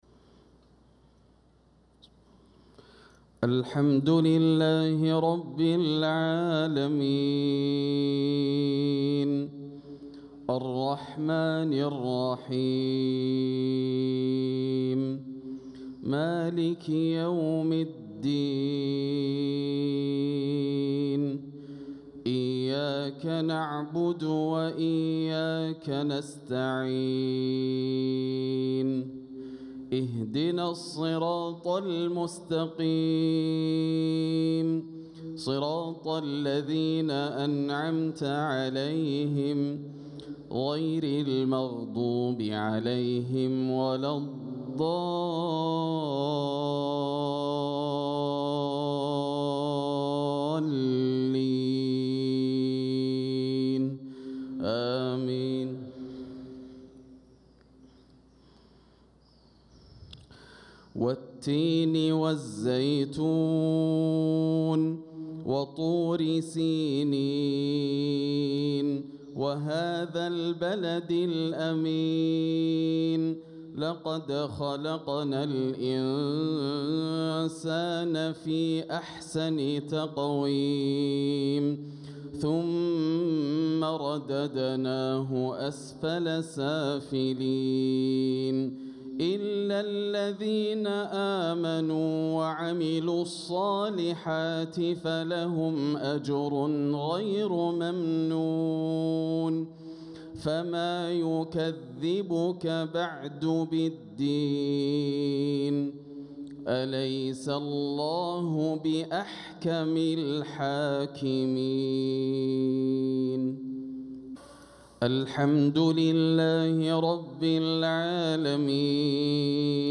صلاة المغرب للقارئ ياسر الدوسري 1 ربيع الأول 1446 هـ
تِلَاوَات الْحَرَمَيْن .